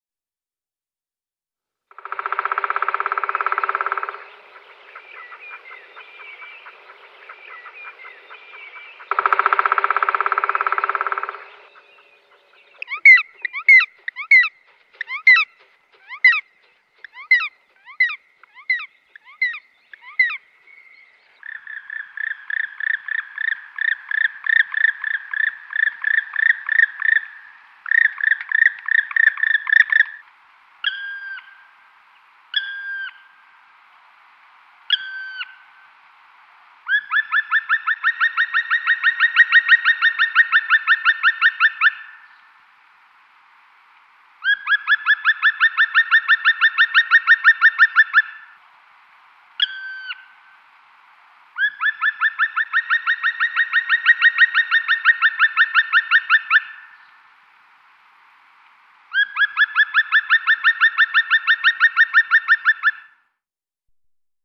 Il canto del Picchio nero
Il canto del Picchio nero .. quello di Roncobello... a_14 a_39
PicchioNero.wma